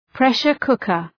Προφορά
{‘preʃər,kʋkər}